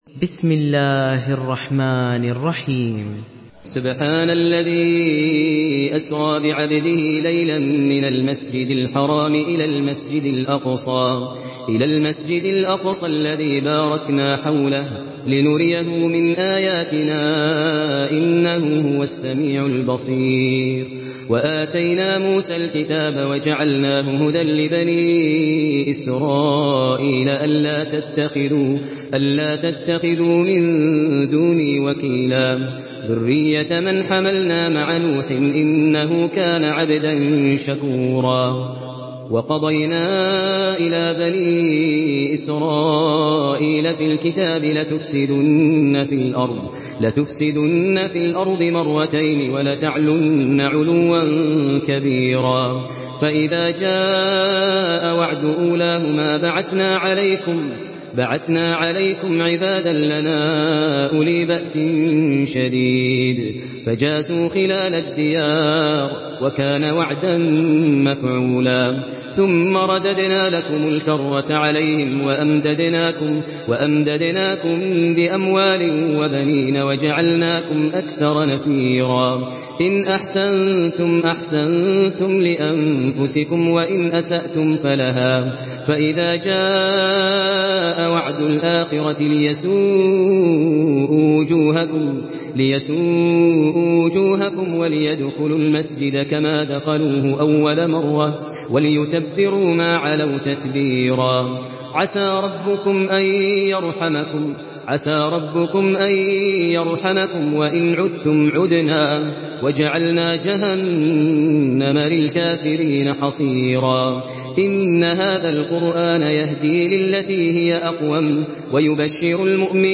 Surah Al-Isra MP3 in the Voice of Maher Al Muaiqly in Hafs Narration
Murattal